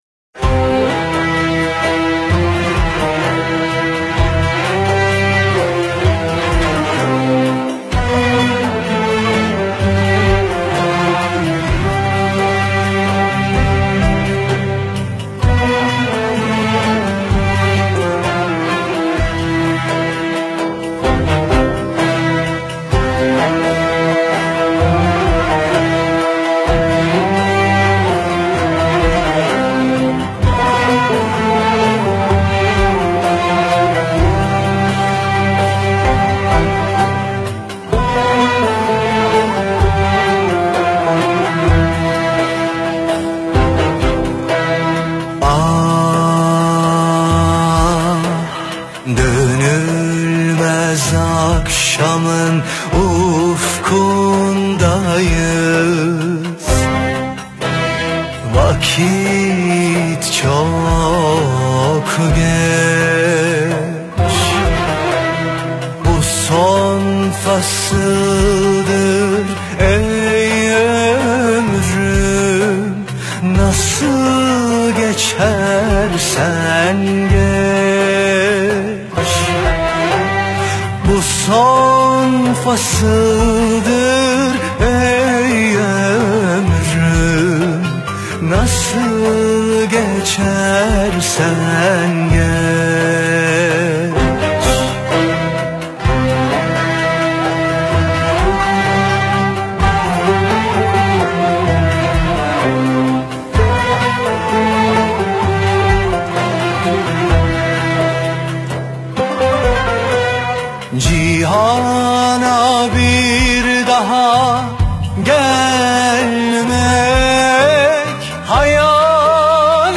موسیقی کلاسیک ترکی